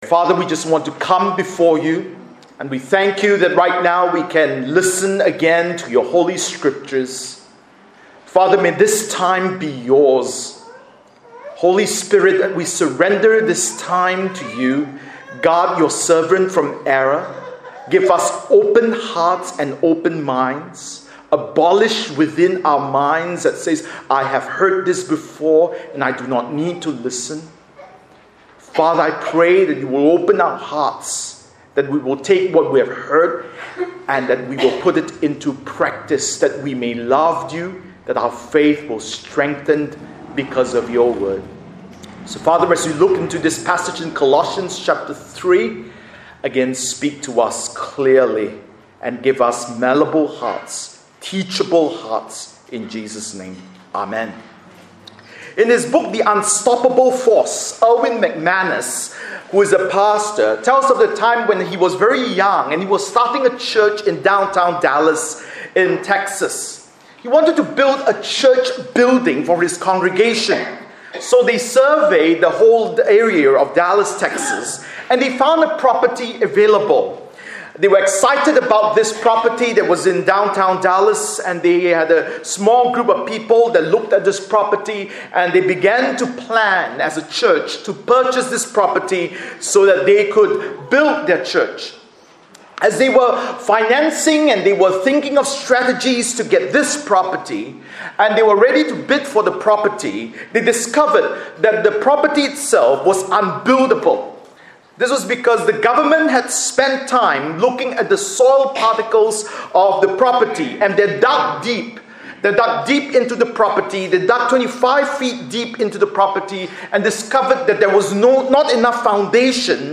Bible Text: Colossians 3:15,16 | Preacher